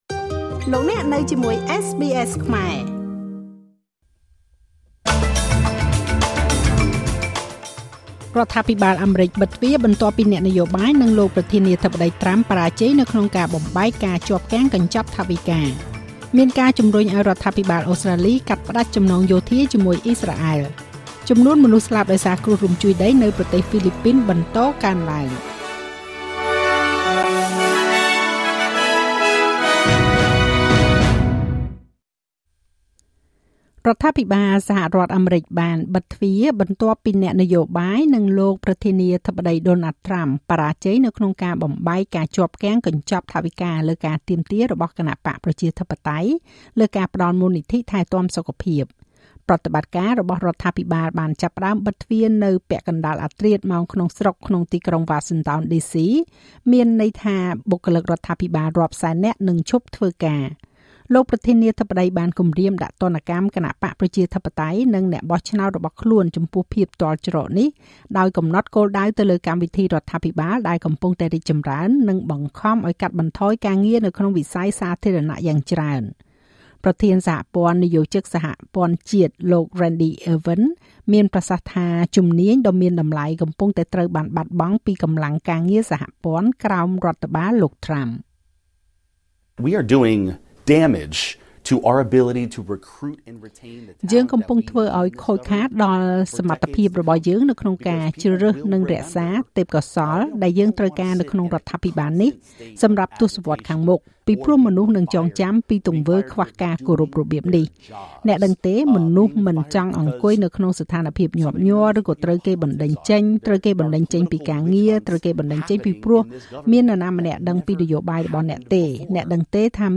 នាទីព័ត៌មានរបស់SBSខ្មែរ សម្រាប់ថ្ងៃពុធ ទី១ ខែតុលា ឆ្នាំ២០២៥